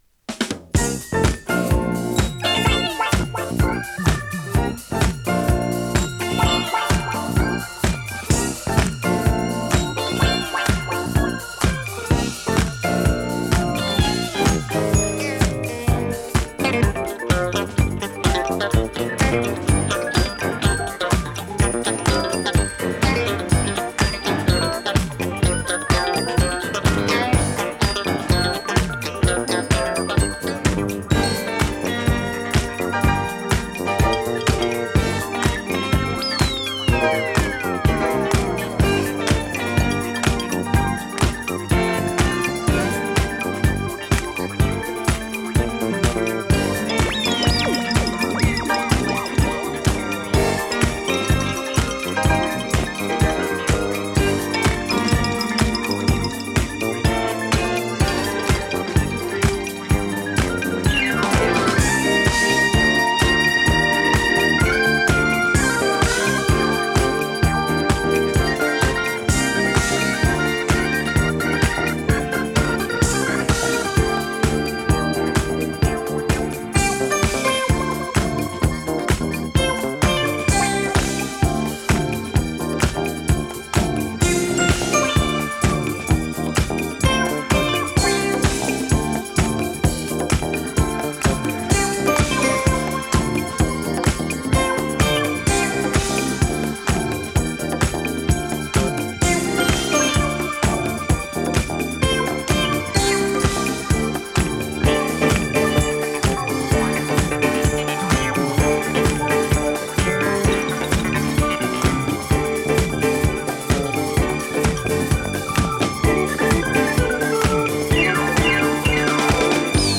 流麗 スペイシー フィリー系 インスト ディスコ
♪Full Length Instrumental Version (5.56)♪